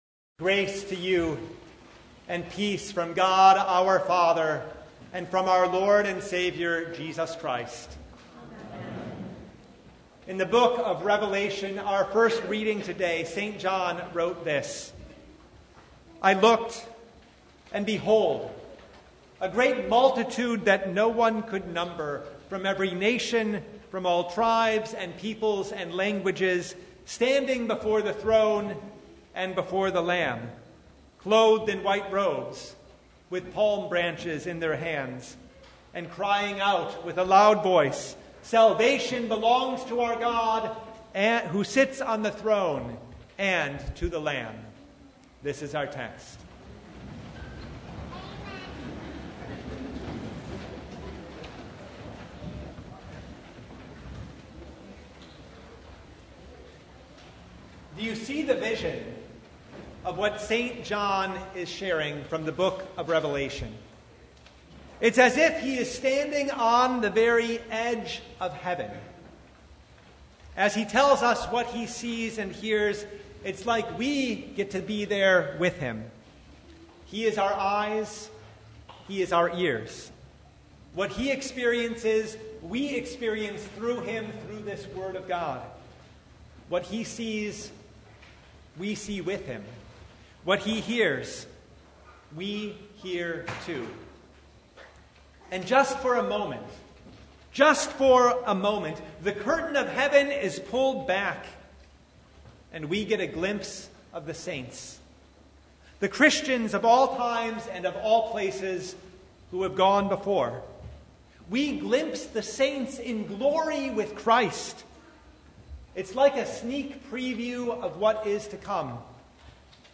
Revelation 7:9-17 Service Type: The Feast of All Saints' Day When we see the saints around the throne